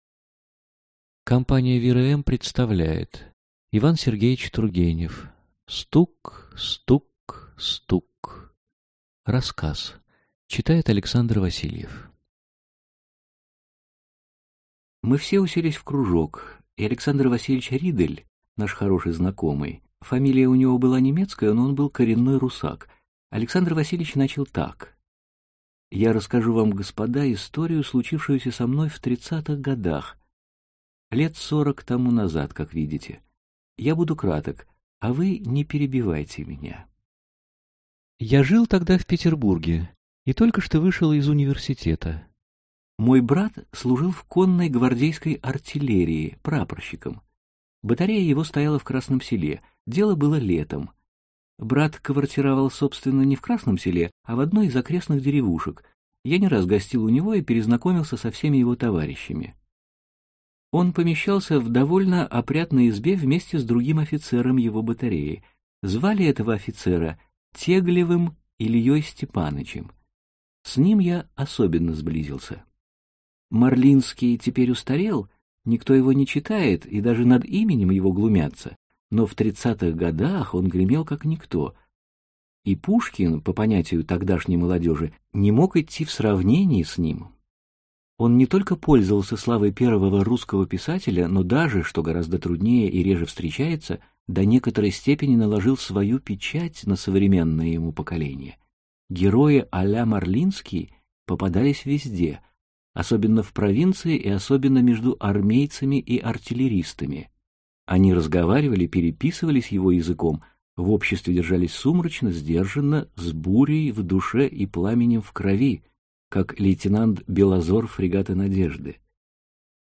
Аудиокнига Стук! Стук! Стук!